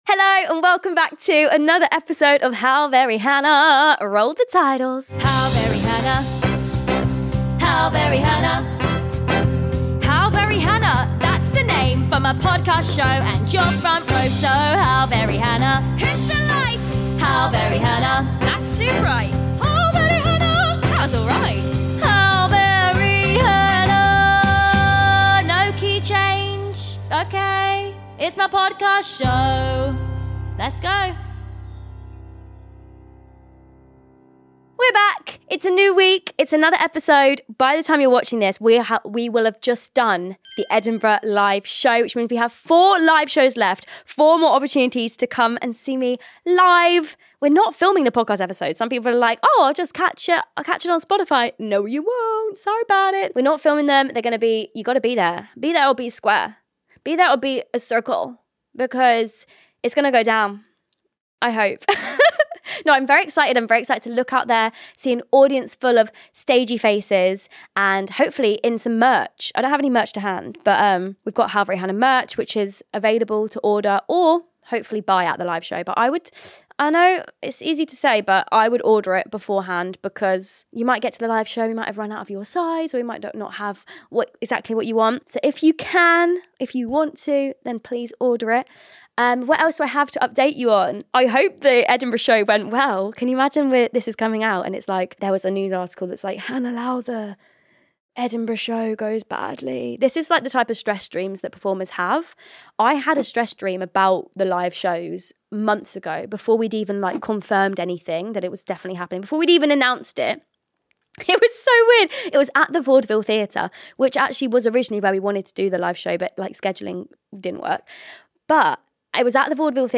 We also answer a dilemma from a listener dating someone in the West End (and feeling a *bit* out of place), before diving into possibly the most chaotic improv game yet, which descended into screeches, fake monologues, and Kitty nearly combusting in the best way.